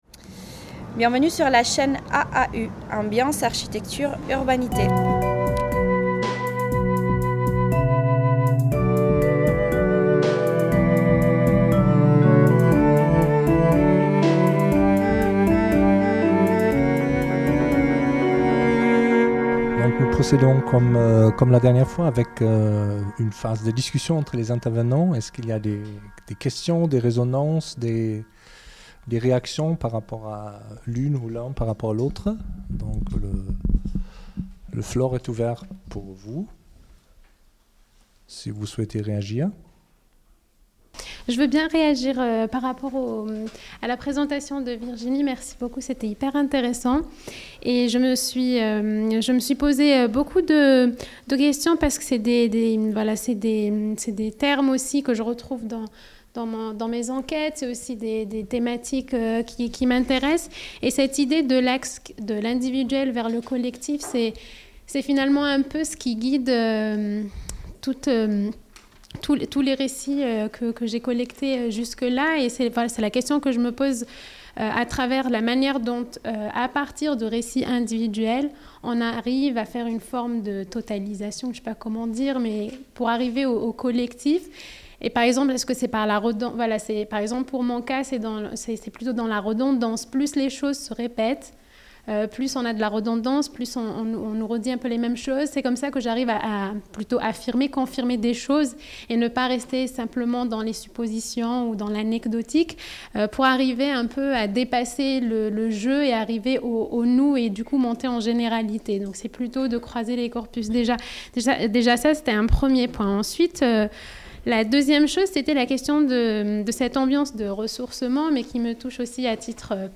Table ronde 2 : Diversité socio-culturelle - Discussion | Canal U